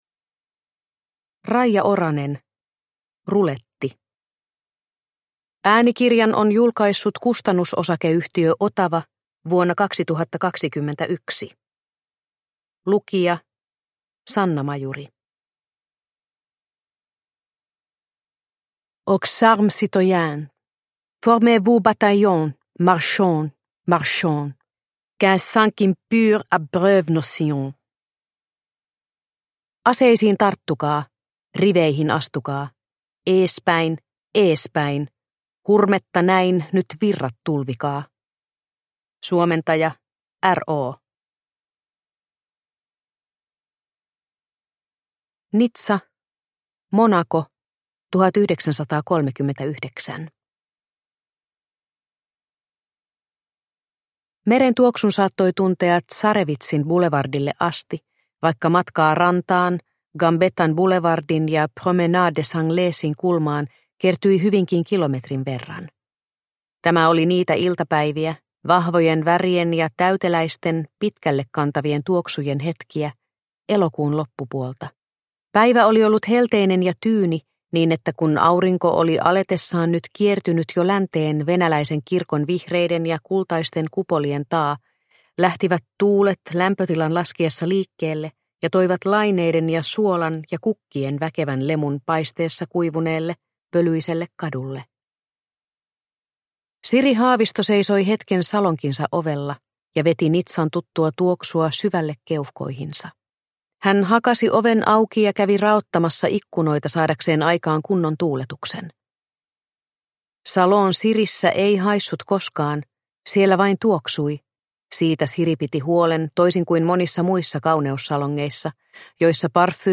Ruletti – Ljudbok – Laddas ner